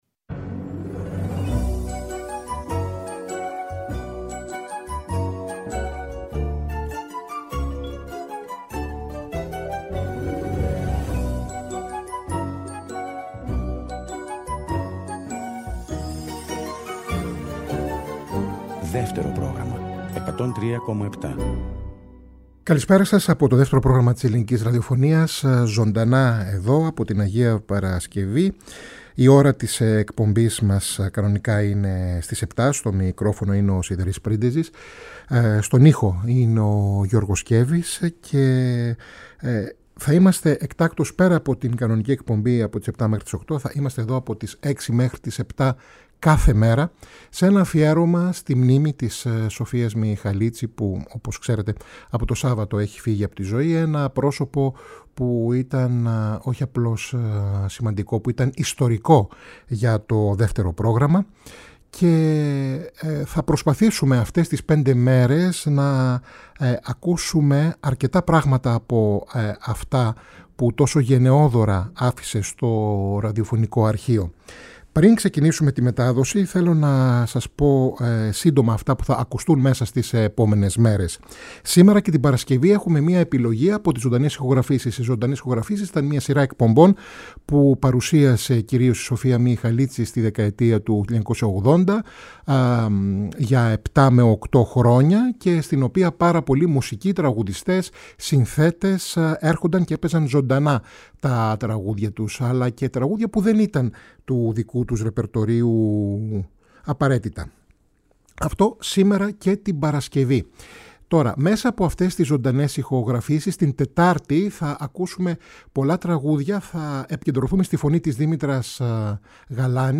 Δευτέρα 15 Νοεμβρίου, ακούμε τραγούδια από τις «Ζωντανές ηχογραφήσεις» μέσα από αναδρομικές εκπομπές.